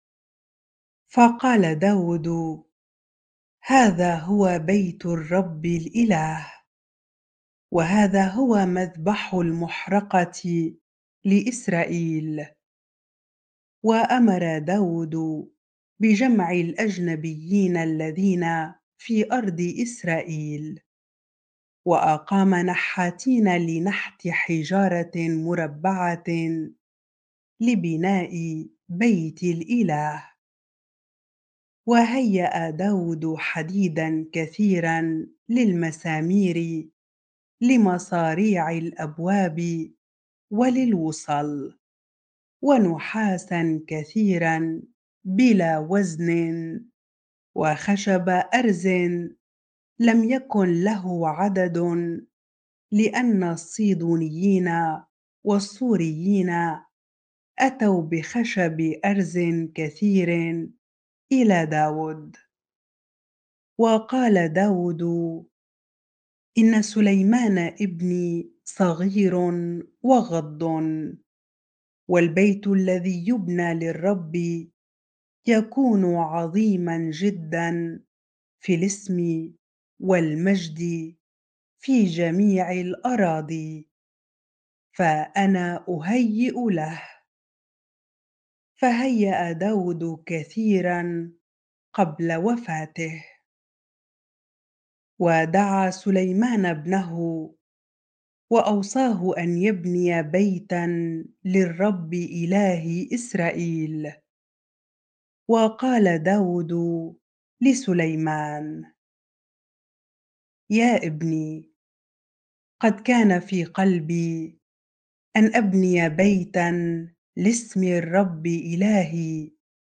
bible-reading-1 Chronicles 22 ar